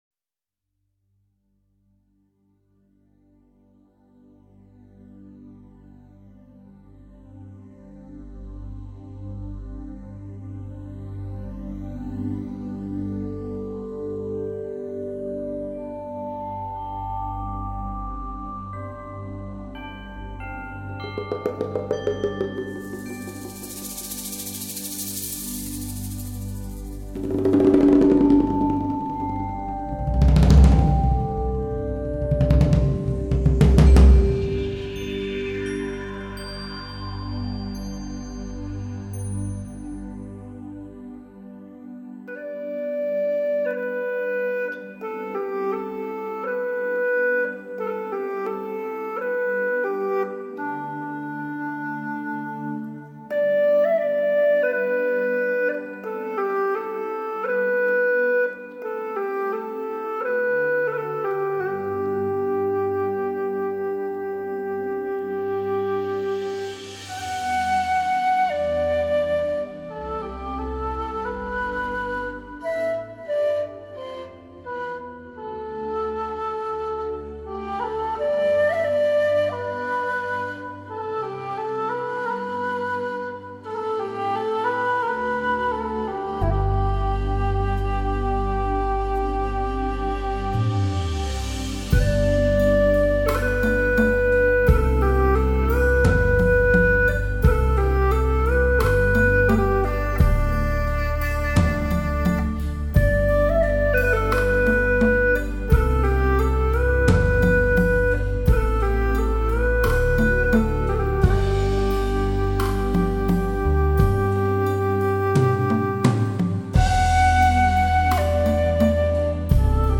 New Age 新音乐探索之作